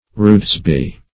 Search Result for " rudesby" : The Collaborative International Dictionary of English v.0.48: Rudesby \Rudes"by\ (r[udd]dz"b[y^]), n. [Etymol. uncertain.]